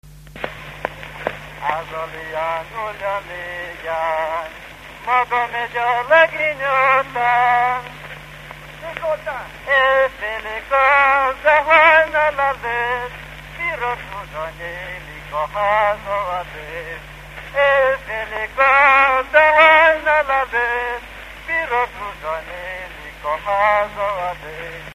Moldva és Bukovina - Moldva - Forrófalva
ének
Stílus: 2. Ereszkedő dúr dallamok
Szótagszám: 8.8.7.9
Kadencia: #7 (5) 5 8